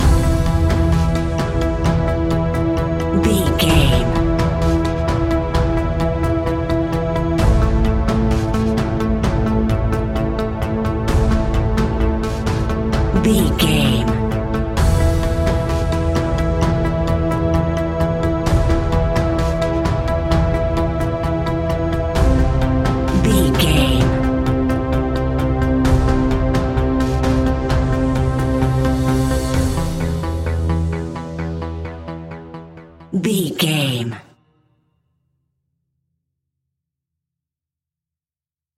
Aeolian/Minor
scary
ominous
dark
eerie
synthesiser
drums
ticking
electronic music
electronic instrumentals